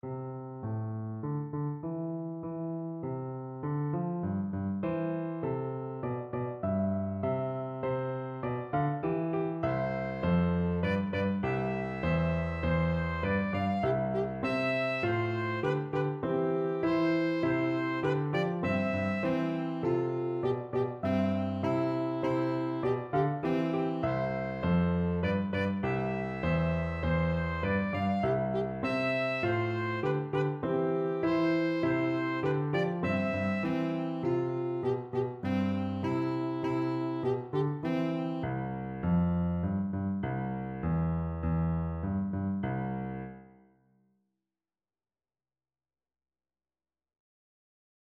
Project 8 : Rounds and catches
A simple I-IV-V-I cadence, separated looking like this : Round 2 C,F,G,C srpead
Unlike the first piece, this one has the same rhythm pattern in every bar, (except for the very last note in the first voice). It is also written for instruments instead of singers, in this case for a piano and a saxophone.